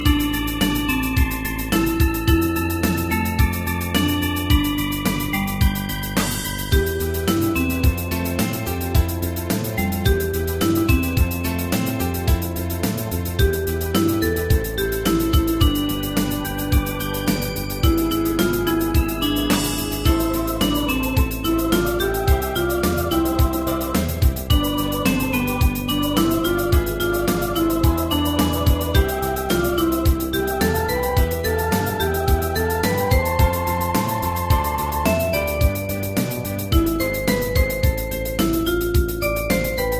大正琴の「楽譜、練習用の音」データのセットをダウンロードで『すぐに』お届け！
カテゴリー: ユニゾン（一斉奏） .
日本のポピュラー